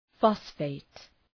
{‘fɒsfeıt}